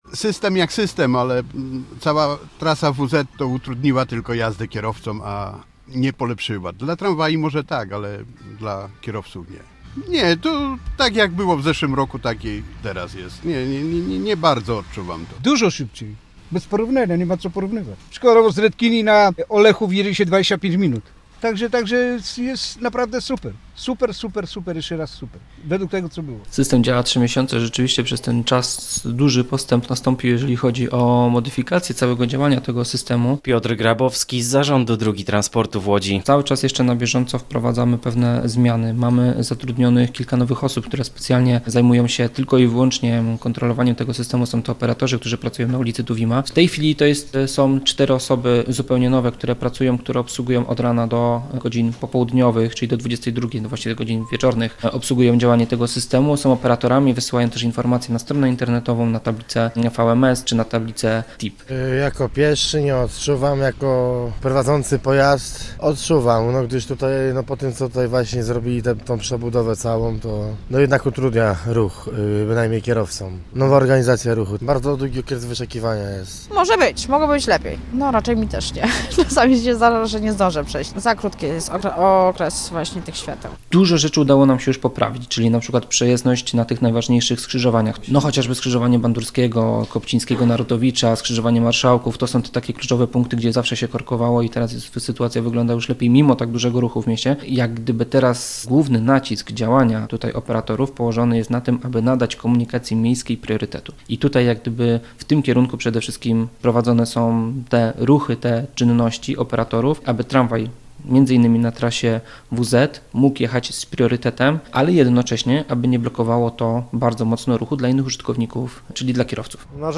Posłuchaj relacji naszego reportera i dowiedz się więcej: Nazwa Plik Autor System sterowania ruchem audio (m4a) audio (oga) ZDJĘCIA, NAGRANIA WIDEO, WIĘCEJ INFORMACJI Z ŁODZI I REGIONU ZNAJDZIESZ W DZIALE “WIADOMOŚCI”.